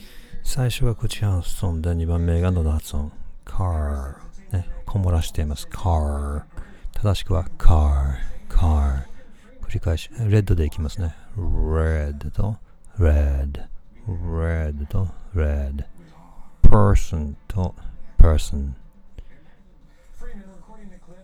いわゆる「英語が上手」と思われている日本人の英語発音 | NipponDream (英語喉）